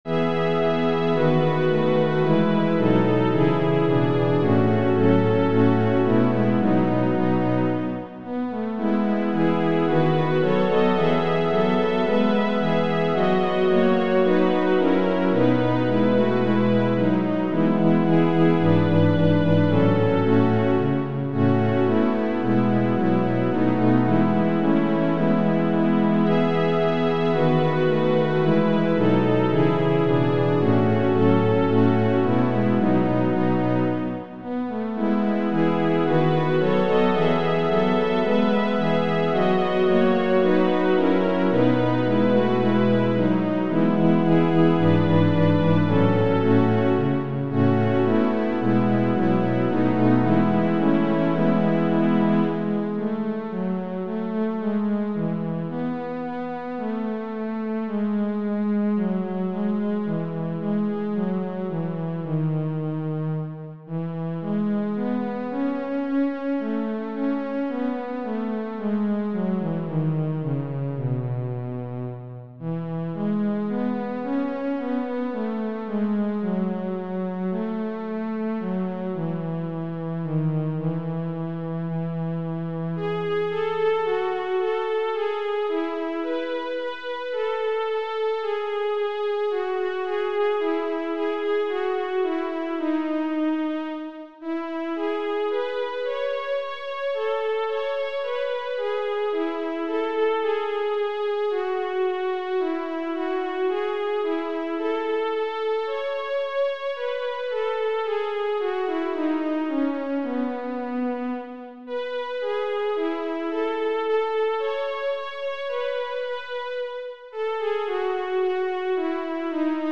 Makundi Nyimbo: Zaburi